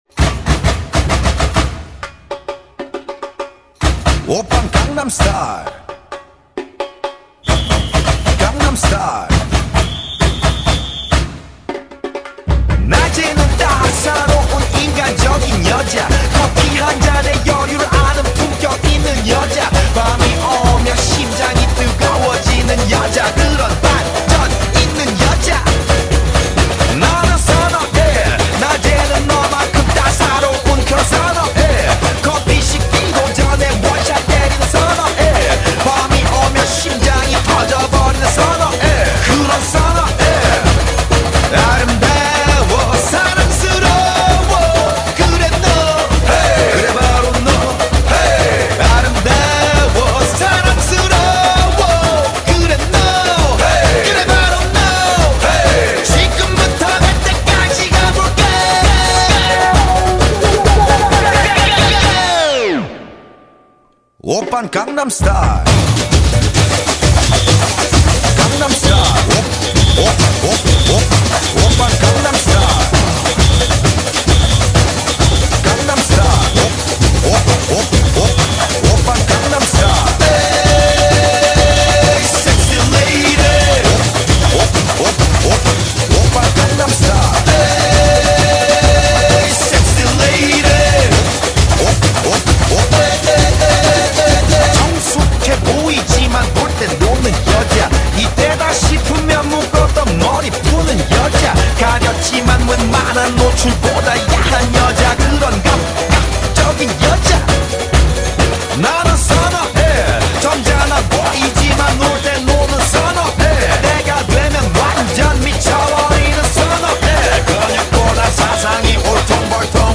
Unknown Samba